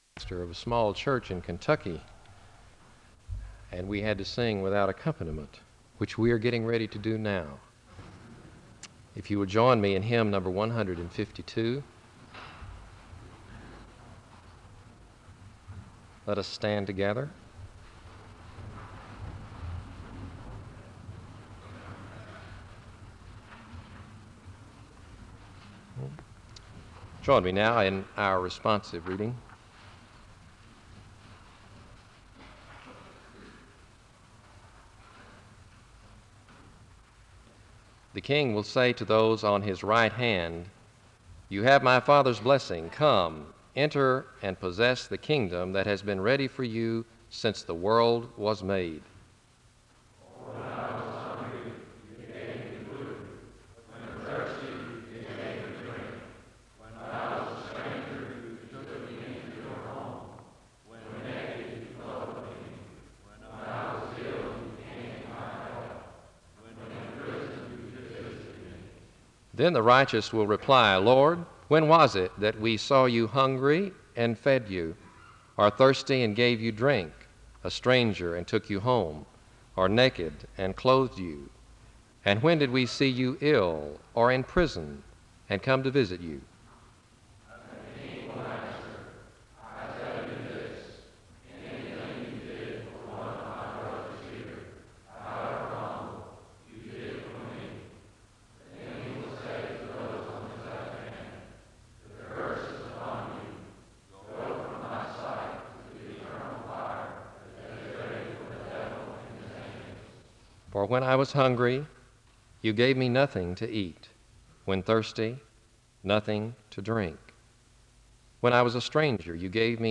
The service begins with a responsive reading and a word of prayer (00:00-05:20).
The service ends with a benediction (24:00-24:45).
SEBTS Chapel and Special Event Recordings SEBTS Chapel and Special Event Recordings